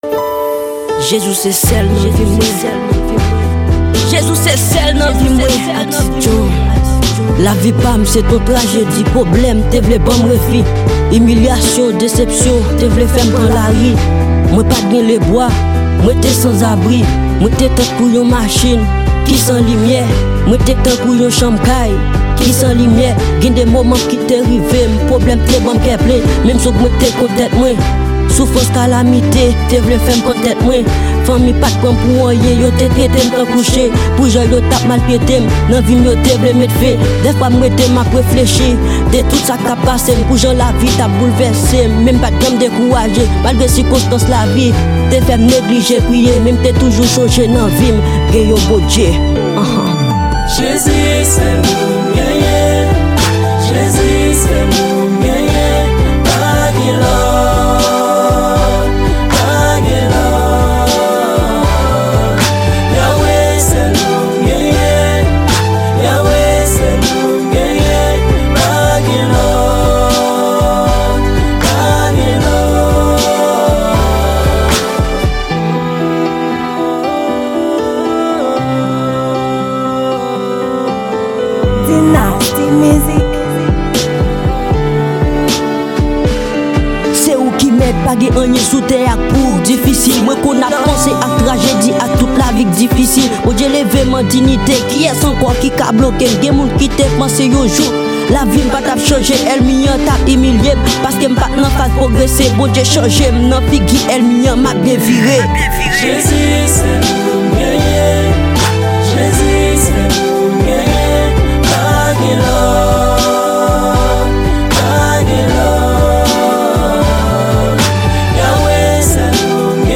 Genre: Rap-Evangelique.